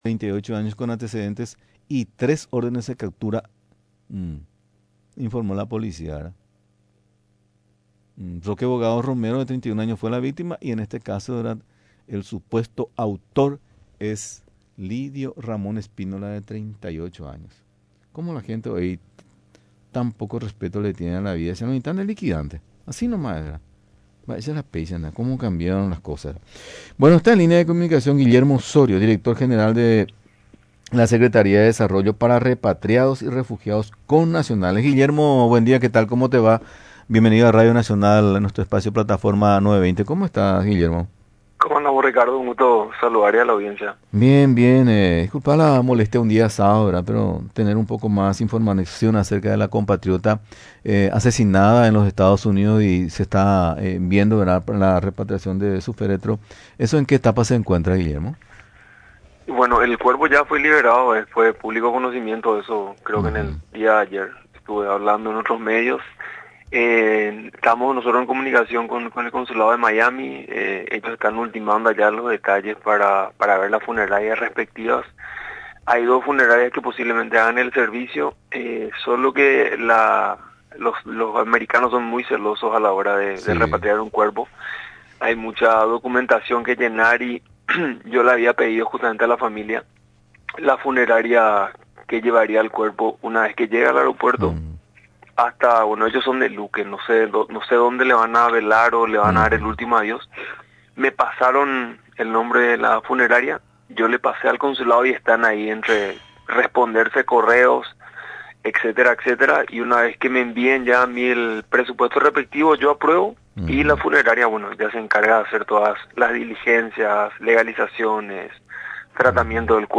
Durante la entrevista en Radio Nacional del Paraguay, explicó los detalles técnicos acerca de la repatriación de los restos.